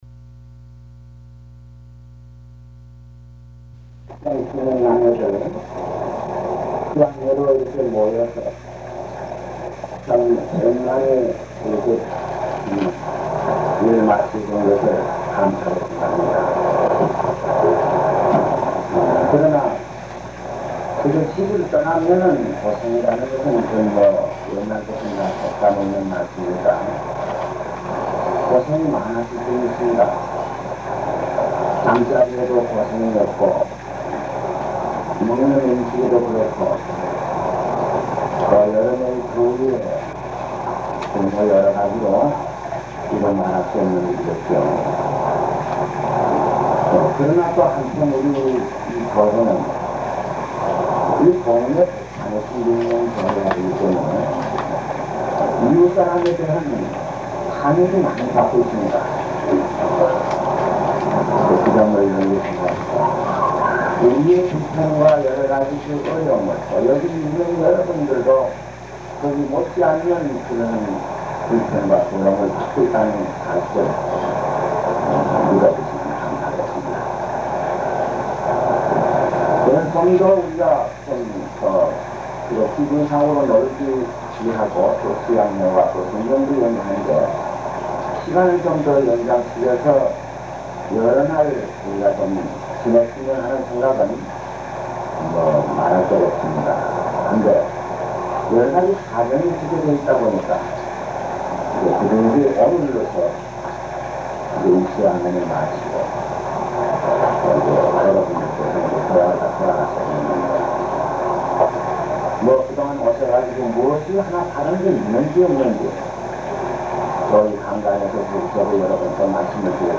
그날과 그때는 아무도 모르나니 (운촌수양회 끝시간)